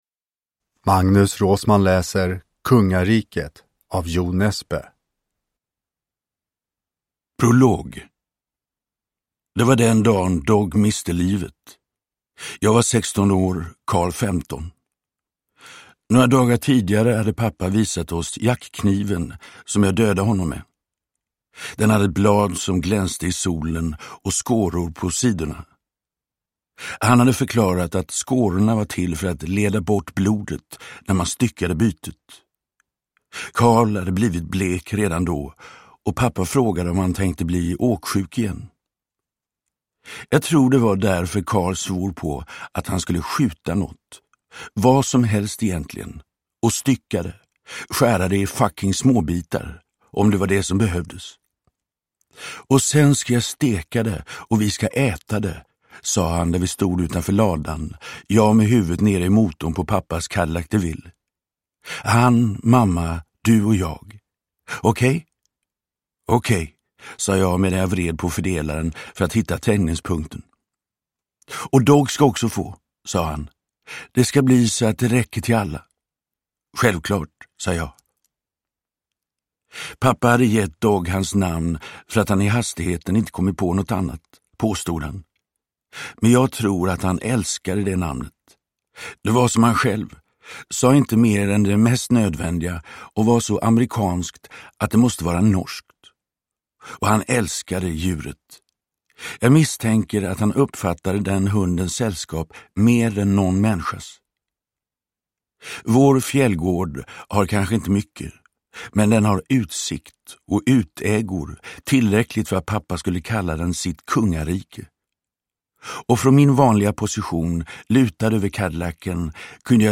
Kungariket – Ljudbok – Laddas ner
Uppläsare: Magnus Roosmann